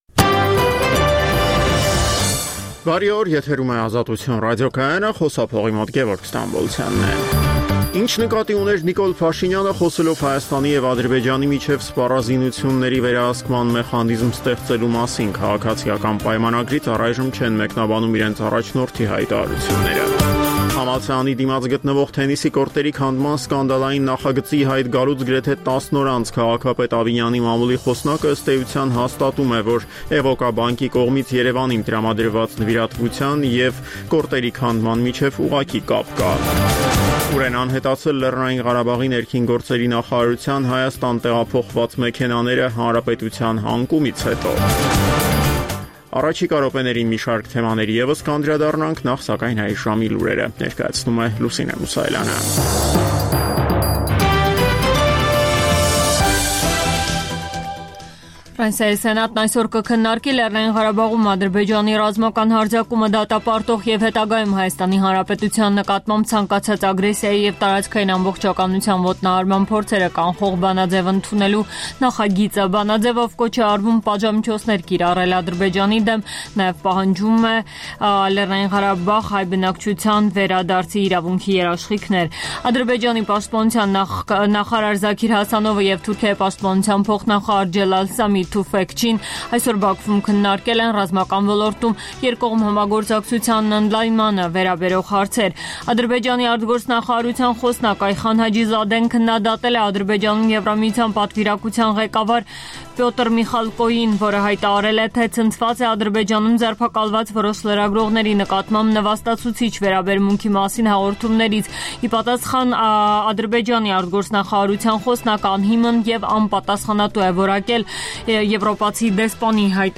Տեղական եւ միջազգային լուրեր, ռեպորտաժներ, հարցազրույցներ: